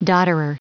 Prononciation du mot dodderer en anglais (fichier audio)
Prononciation du mot : dodderer